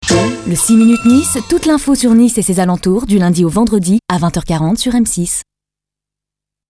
Souriant
Voix off